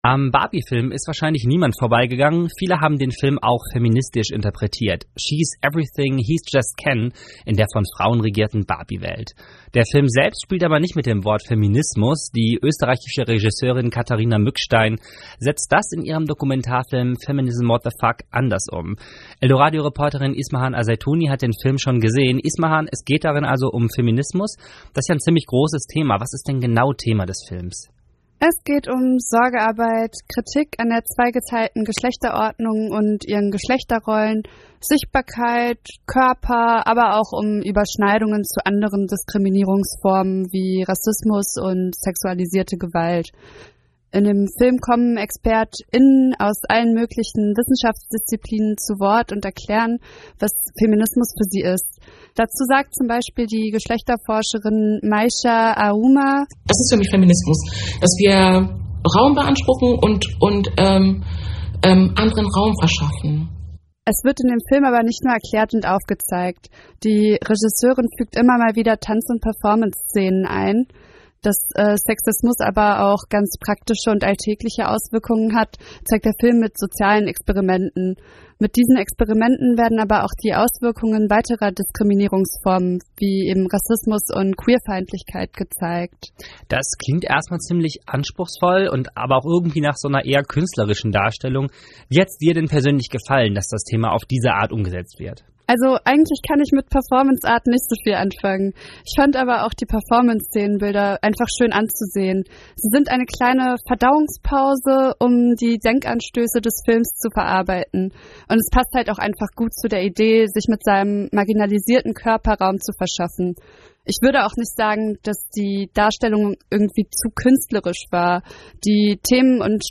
Kino-Rezension: Feminism WTF